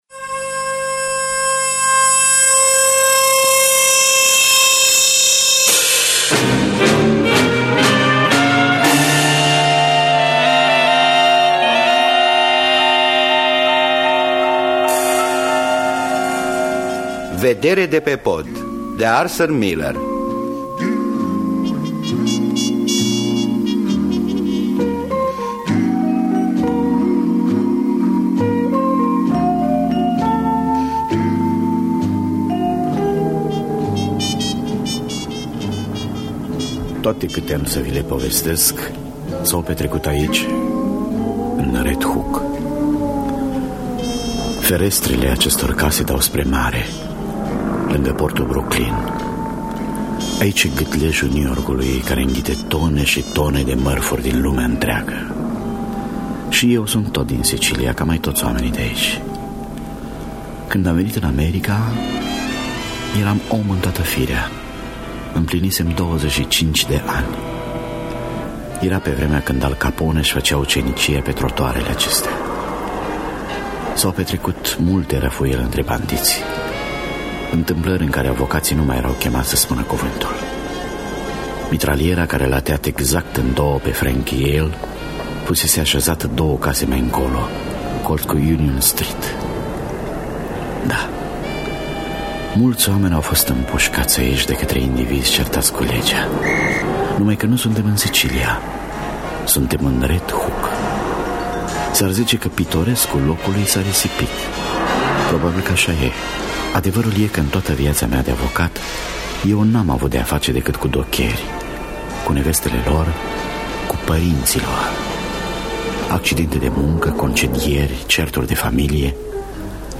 Vedere de pe pod de Arthur Miller – Teatru Radiofonic Online